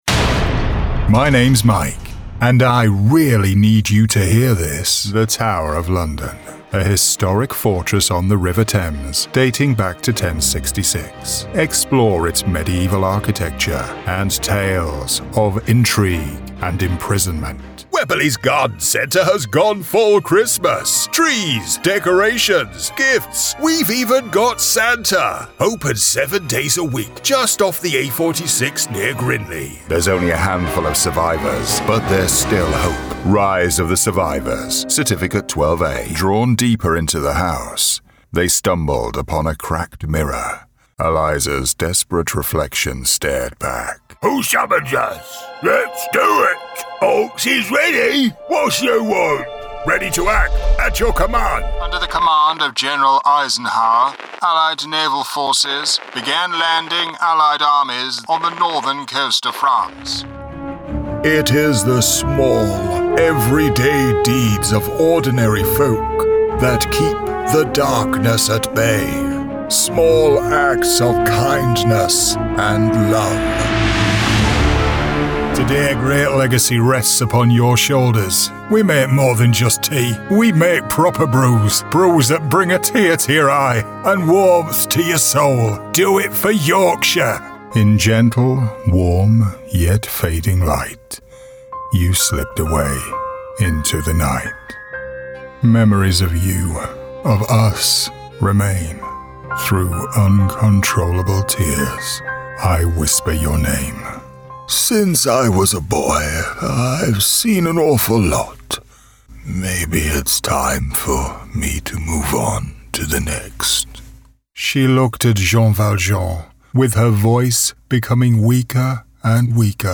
Naturally deep, warm and friendly, distinguished, charismatic, versatile